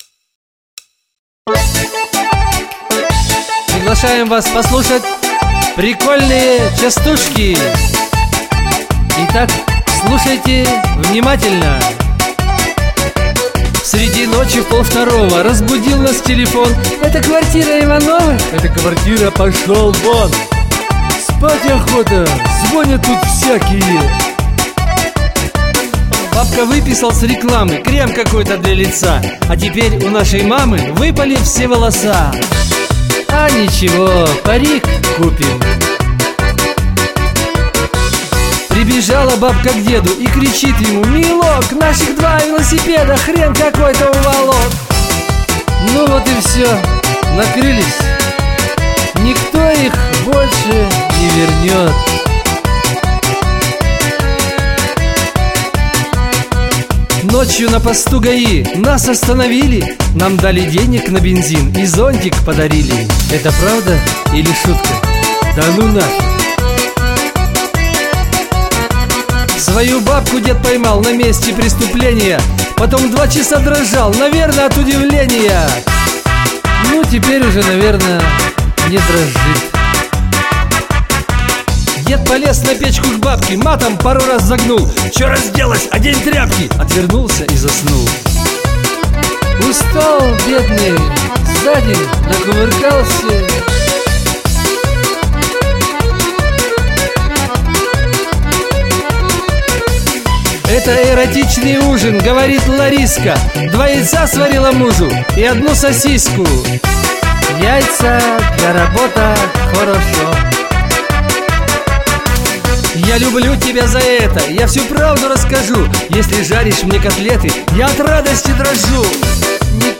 pesni_dlja_dushi___prikolnie_chastushki_.mp3